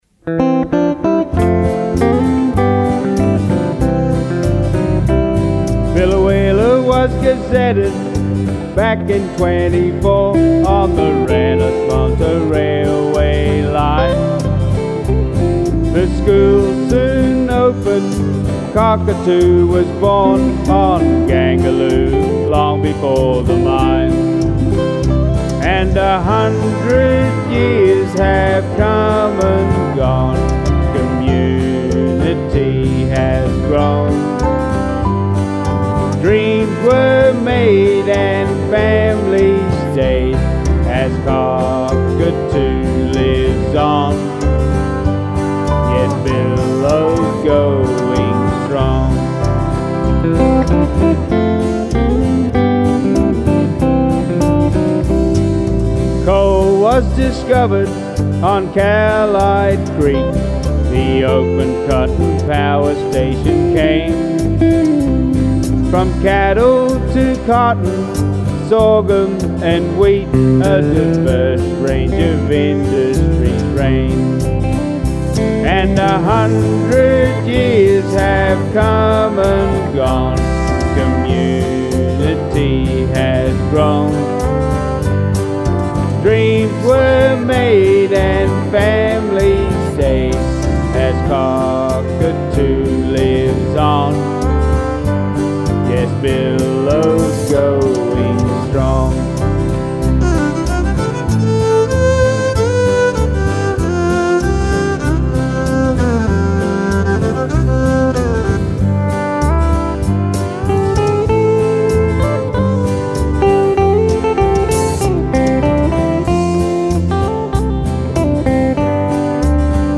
Award winning bush balladeer